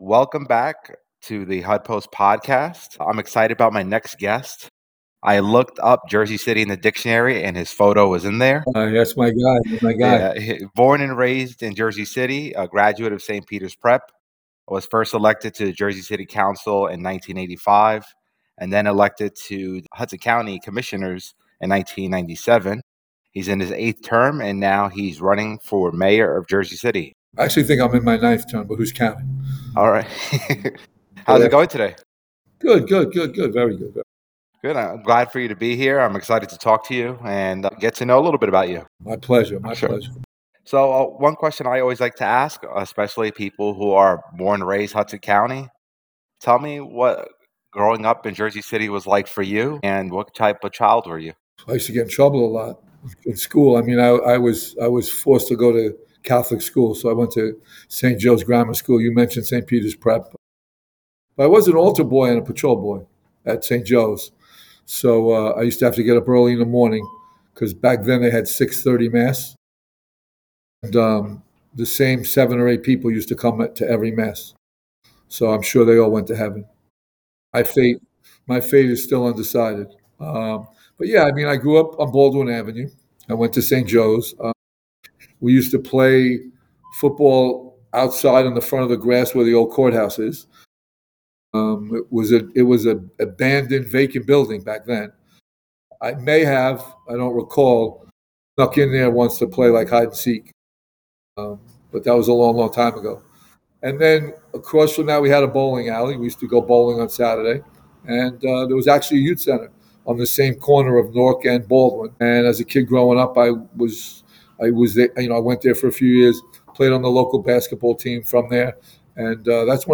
HudPost Interview